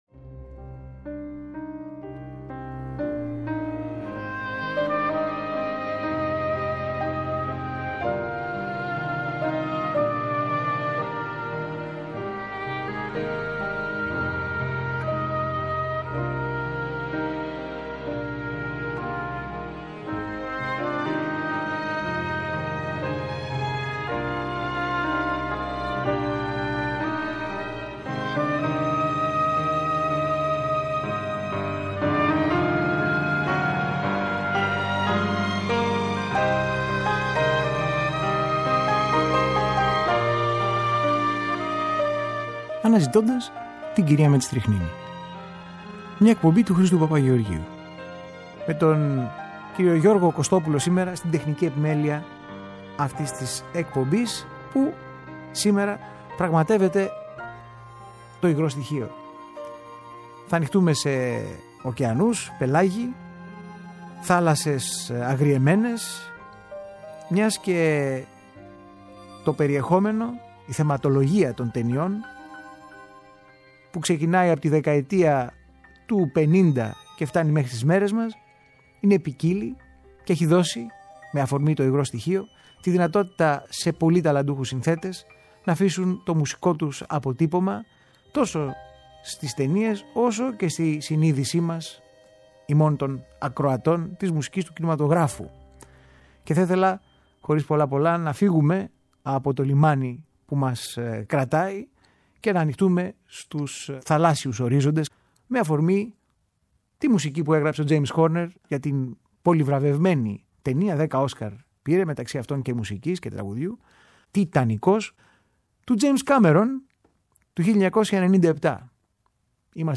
Μια ανθολογία από μουσικές που σχετίζονται με το νερό ξεκινώντας από τους ωκεανούς τα πελάγη και τις εξερευνήσεις σε όλα τα μήκη, πλάτη και βάθη του γνωστού και άγνωστου κόσμου.
Κινηματογραφικές Μουσικές για το Υγρό Στοιχείο – Μέρος 1ο Μια ανθολογία από μουσικές που σχετίζονται με το νερό ξεκινώντας από τους ωκεανούς τα πελάγη και τις εξερευνήσεις σε όλα τα μήκη, πλάτη και βάθη του γνωστού και άγνωστου κόσμου.